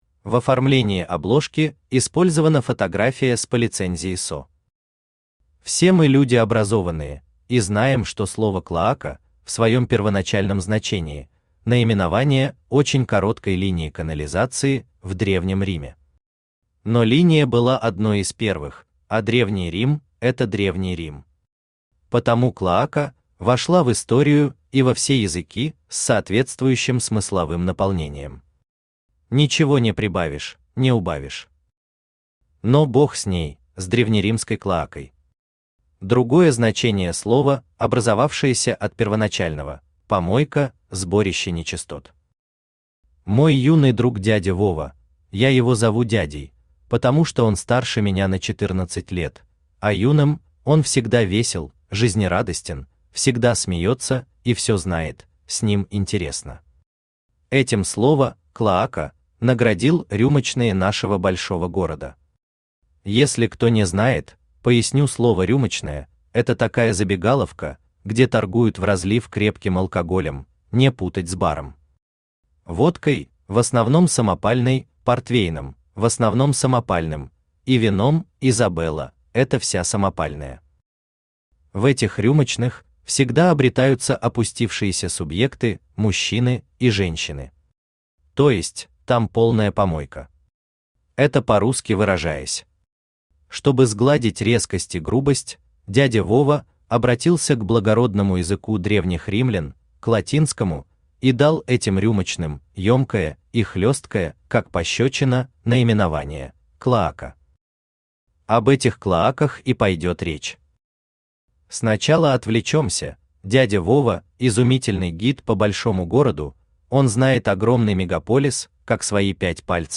Aудиокнига Клоаки Большого города Автор Алексей Николаевич Наст Читает аудиокнигу Авточтец ЛитРес.